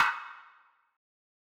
Snare [40].wav